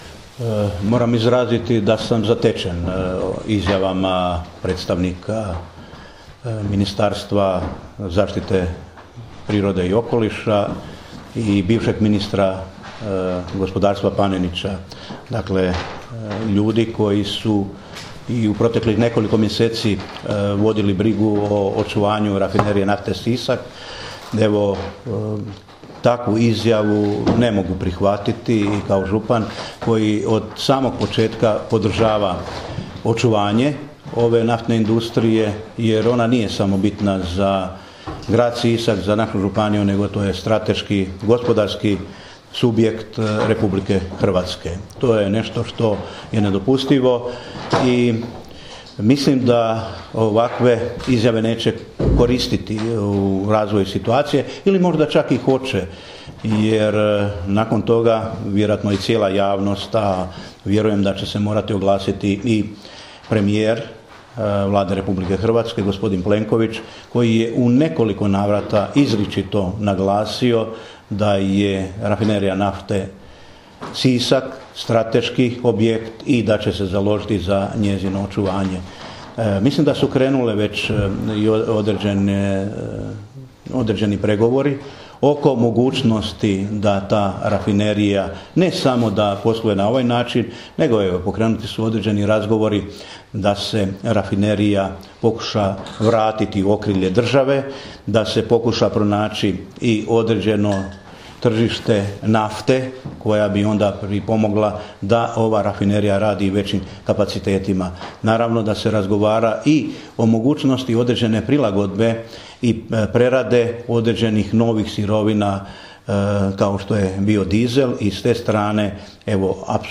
Izjava župana Žinića: